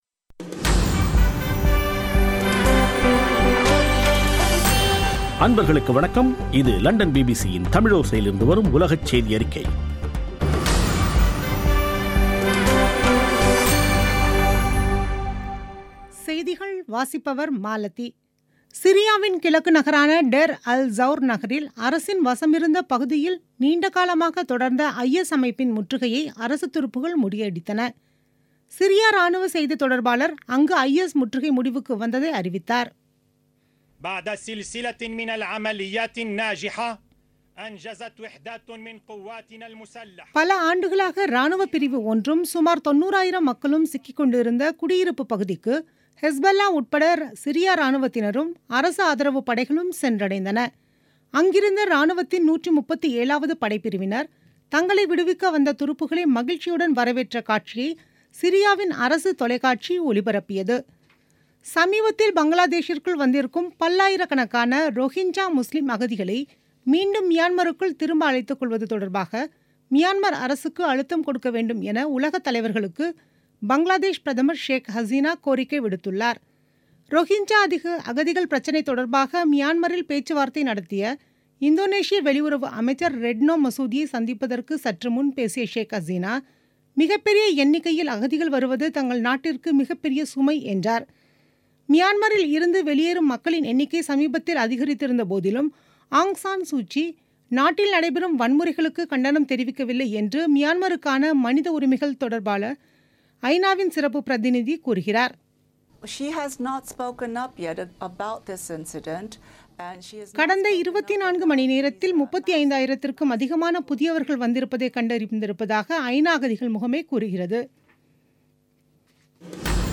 பிபிசி தமிழோசை செய்தியறிக்கை (05.09.2017)